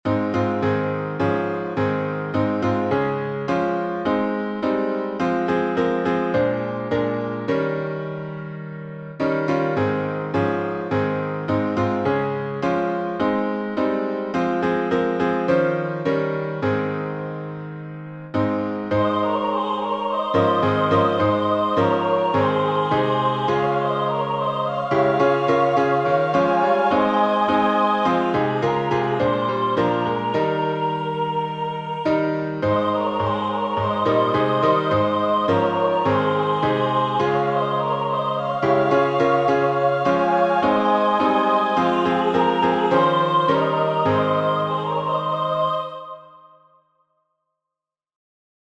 Words by James W. Acuff (1864-1937), 1906Tune: GLORYLAND by Emmett S. Dean (1876-1951)Key signature: A flat major (4 flats)Time signature: 4/4Meter: 10.8.10.8. with RefrainPublic Domain1.